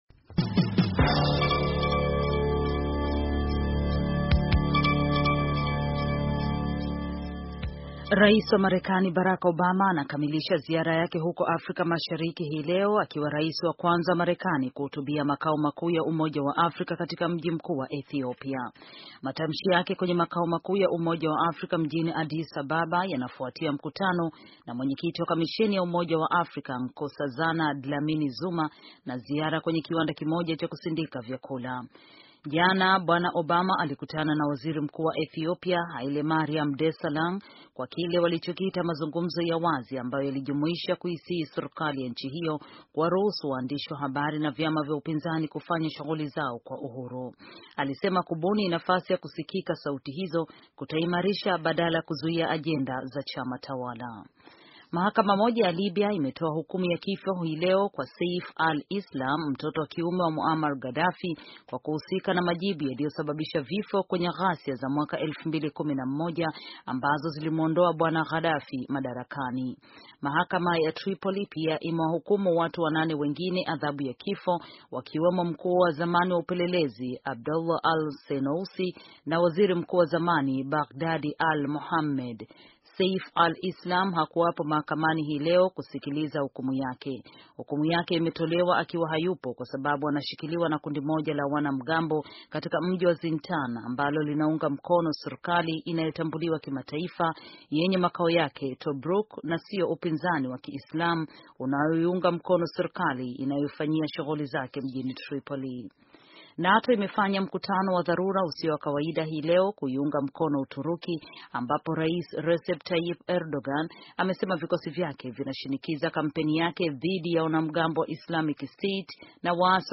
Taarifa ya habari - 4:47